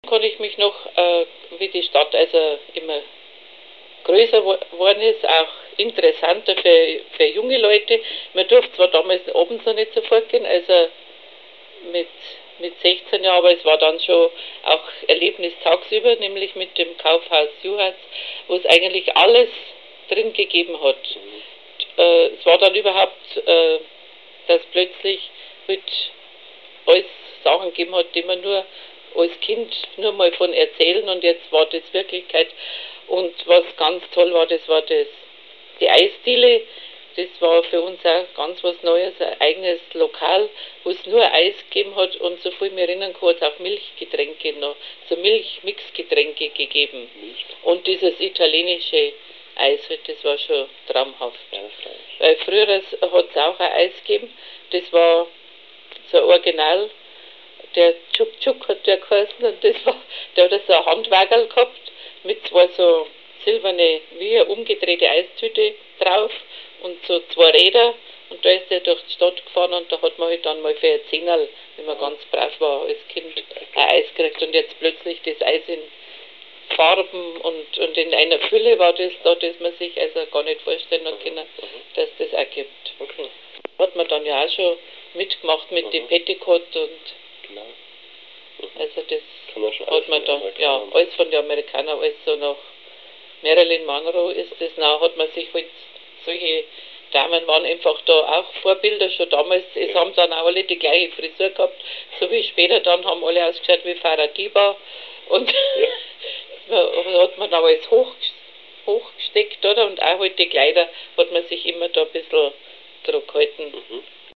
Zeitzeugen